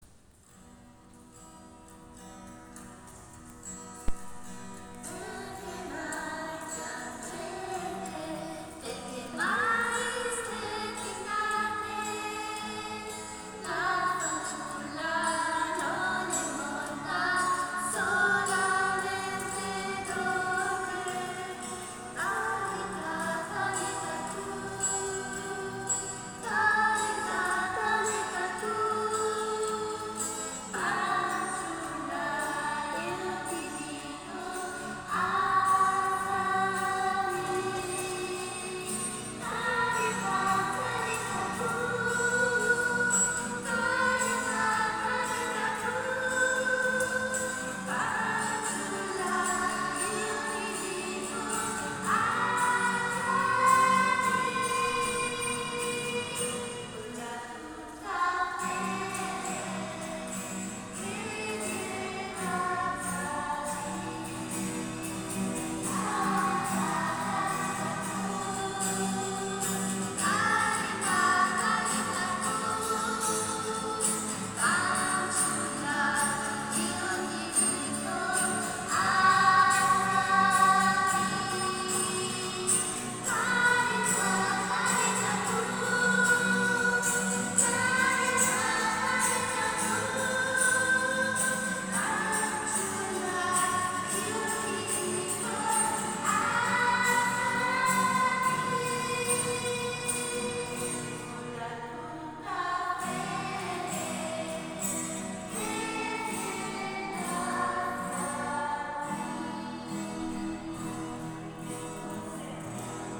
Canto per la Decina di Rosario e Parola di Dio: Talità kum.
Talita-kum-bambini.mp3